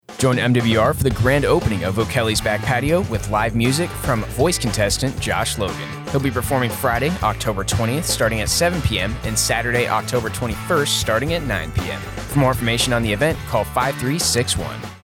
A radio spot informing listeners...